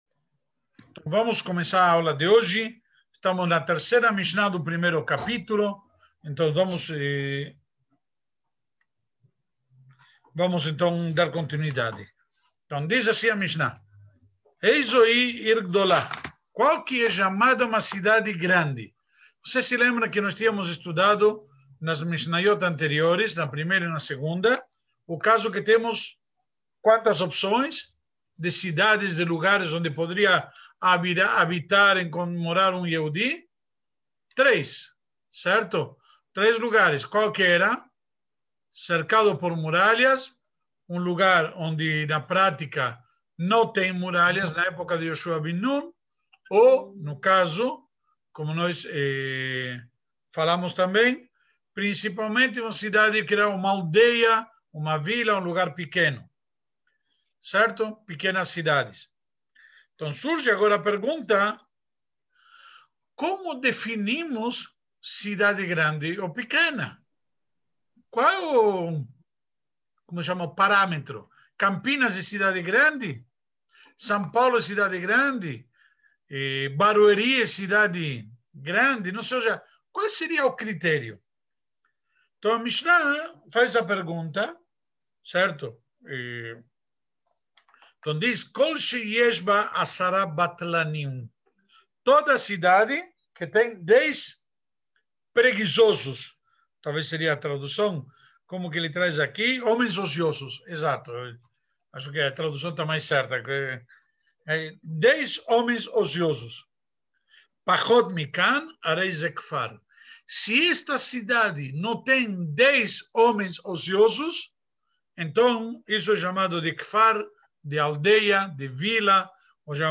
Projeto Mishná 5781- aula 2